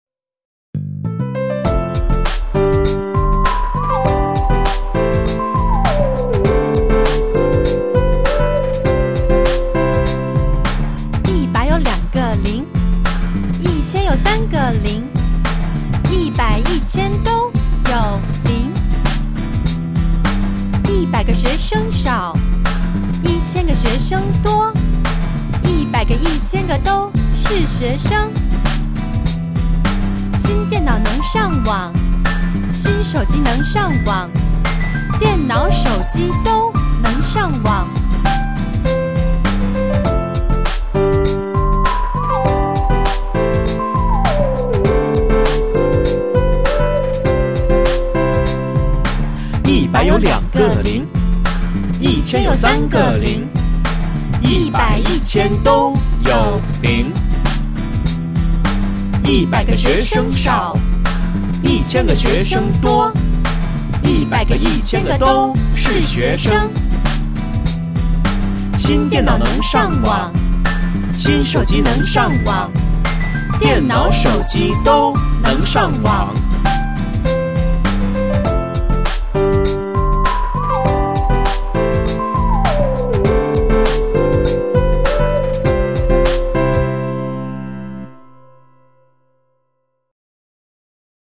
Cùng hát nhé